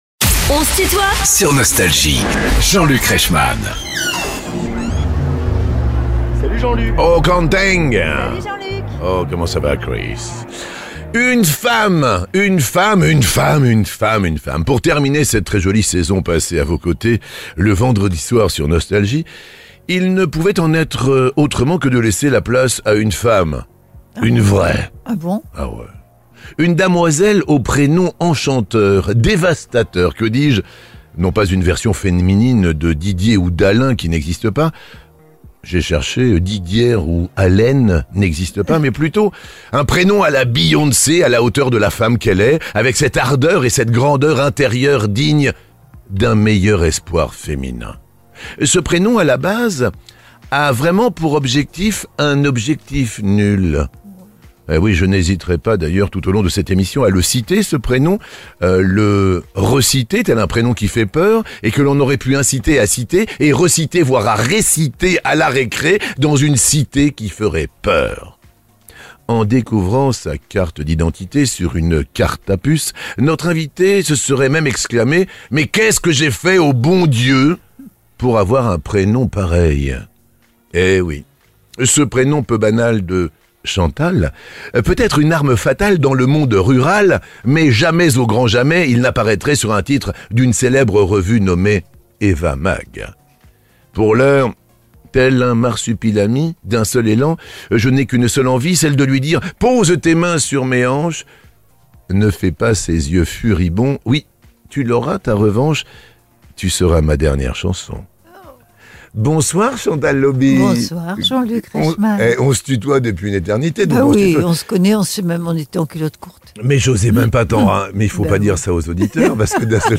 Les interviews